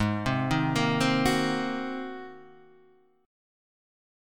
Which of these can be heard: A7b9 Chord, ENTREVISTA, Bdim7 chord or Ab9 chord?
Ab9 chord